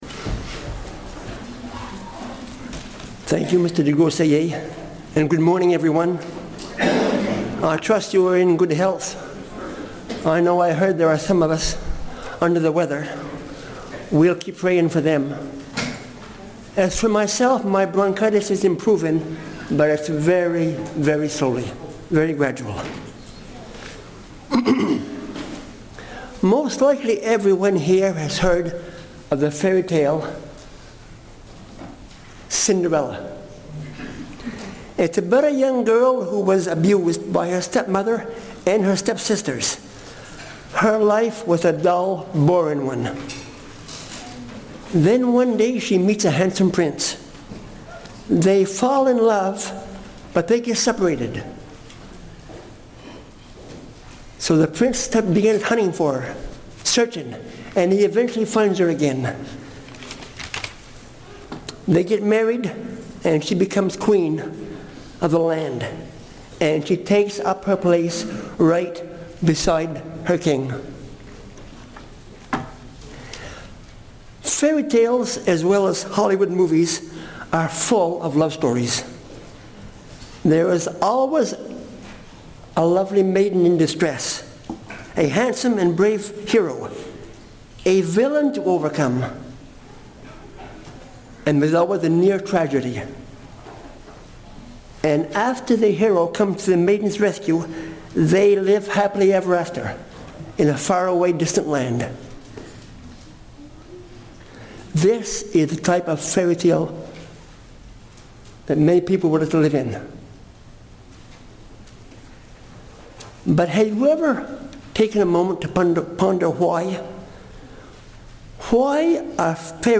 Feast of Tabernacles Sermon marriage supper of the lamb Studying the bible?